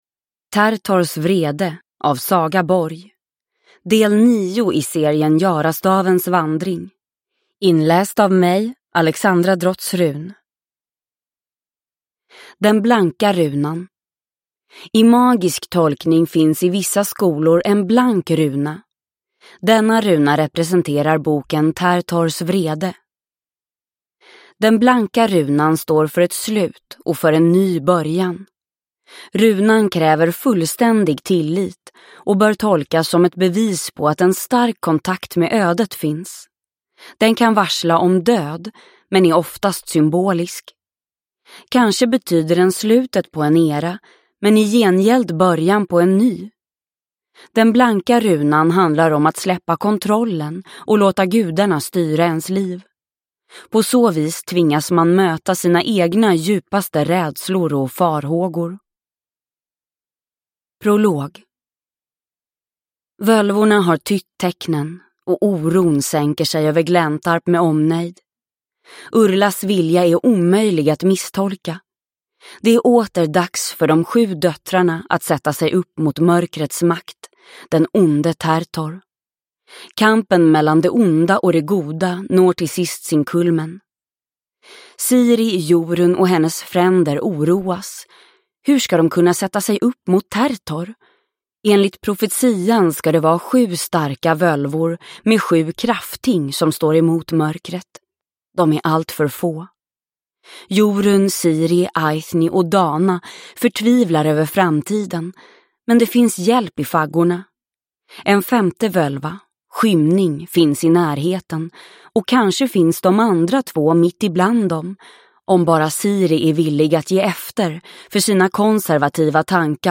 Tertors vrede – Ljudbok – Laddas ner